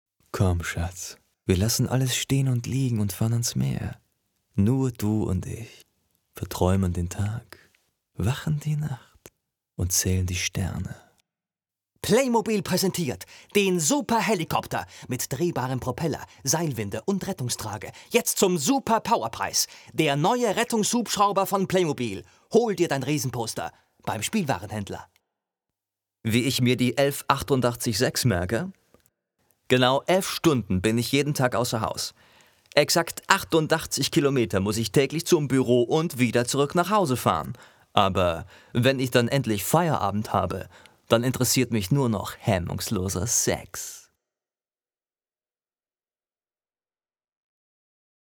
Sprecher für Werbung, Off, Industrie, eLearning,
Sprechprobe: Werbung (Muttersprache):